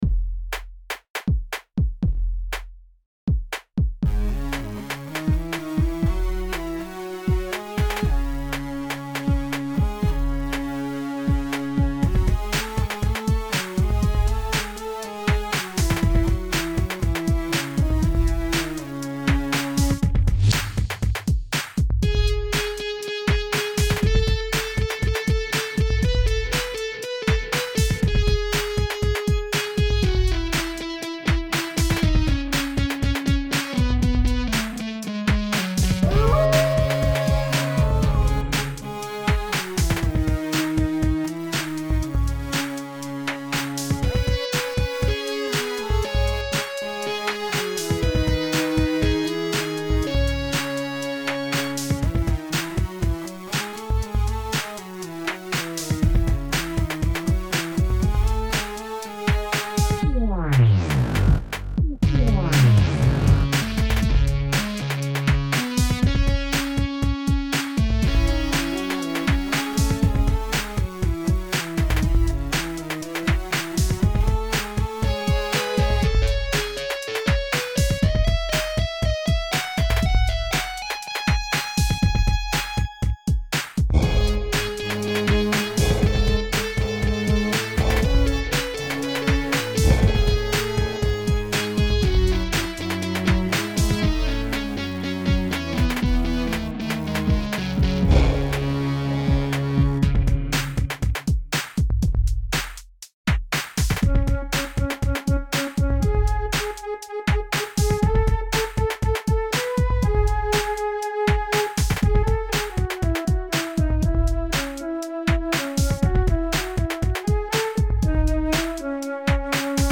Posted in Dubstep, Other Comments Off on